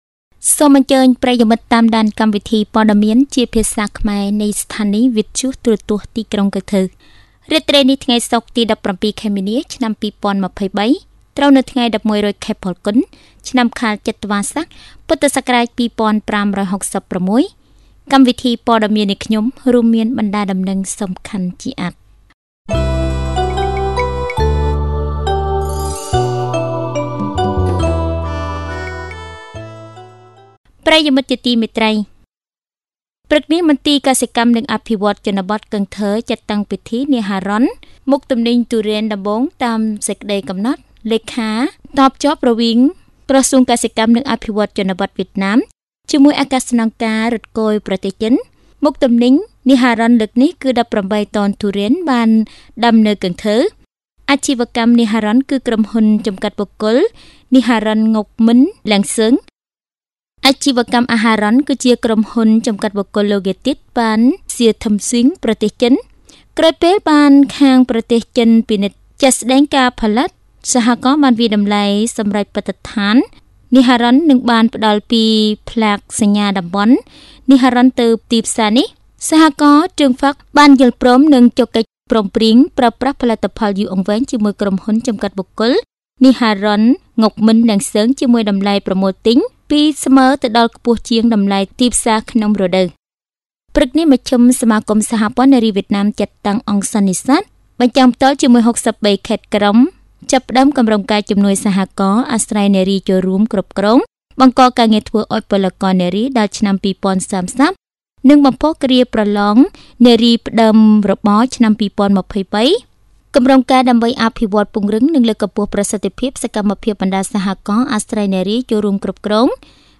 Bản tin tiếng Khmer tối 17/3/2023